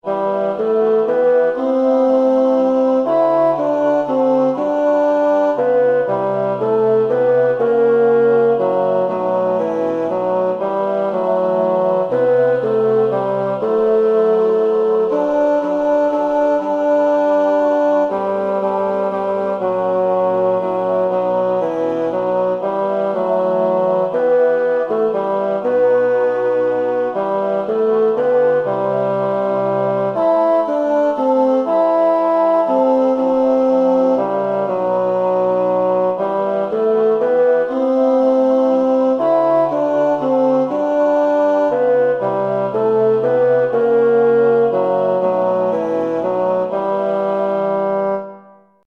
Ande och Liv tenor
ande av liv_tenor.mp3